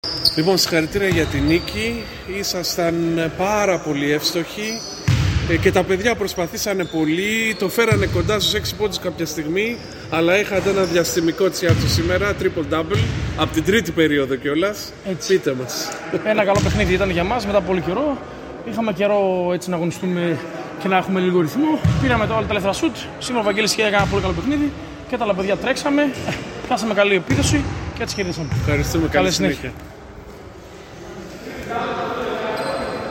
GAME INTERVIEWS